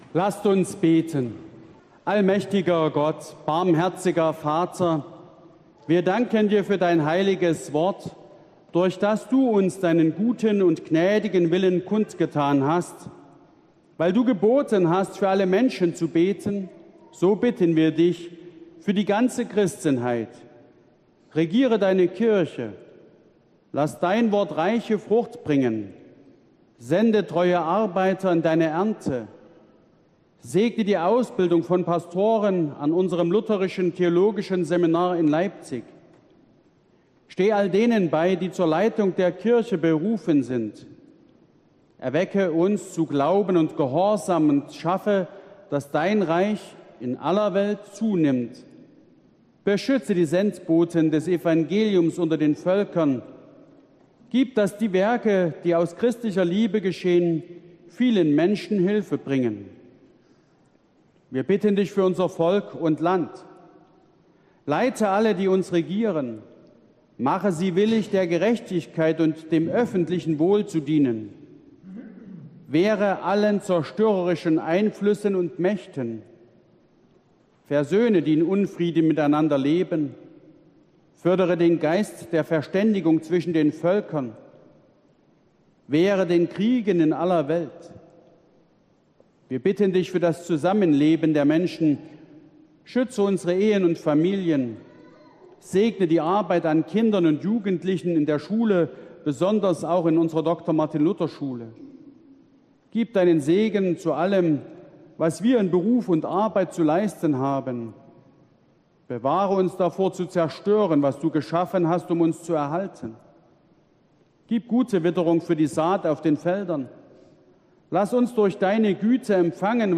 Gottesdienst am 04.07.2021